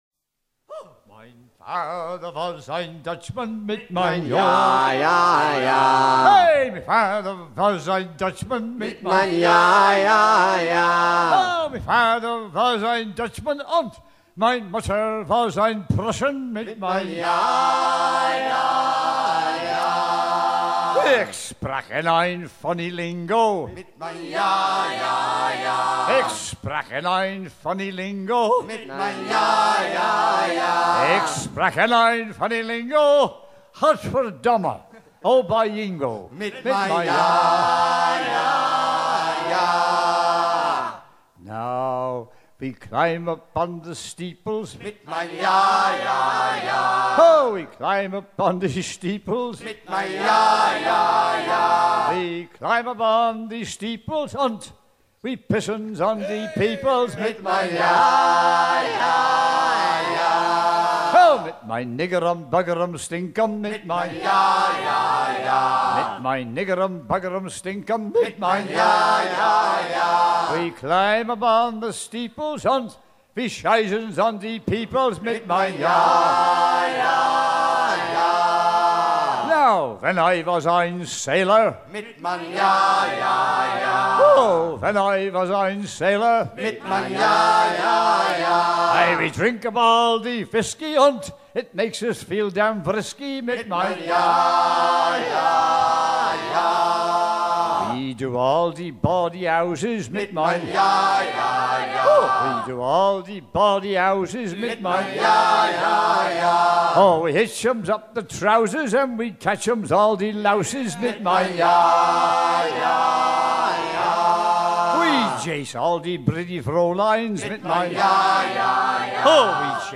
shanty en allemand approximatif, assez cru